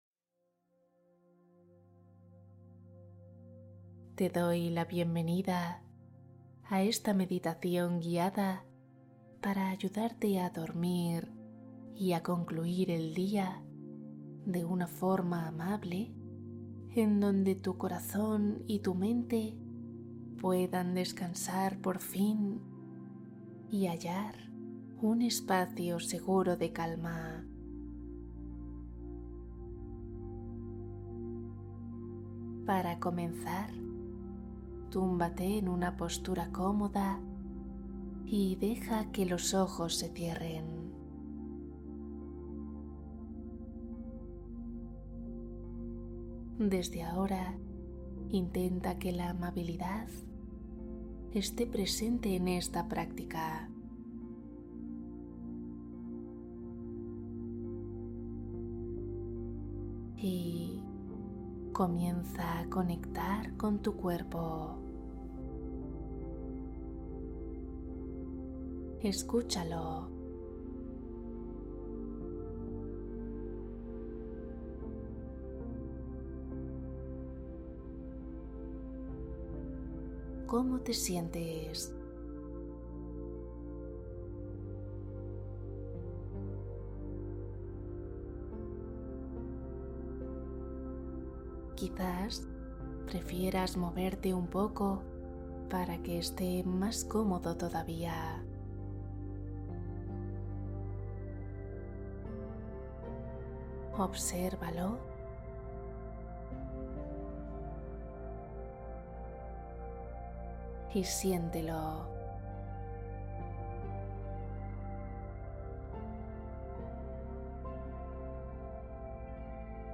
Adiós ansiedad Meditación guiada para dormir y encontrar calma